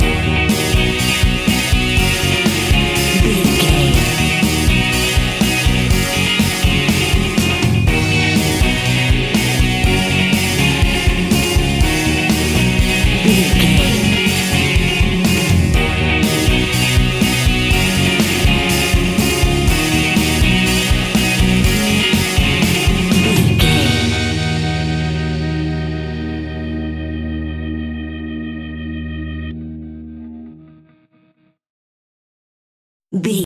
Ionian/Major
E♭
pop rock
indie pop
fun
energetic
uplifting
electric guitar
Distorted Guitar
Rock Bass
Rock Drums
hammond organ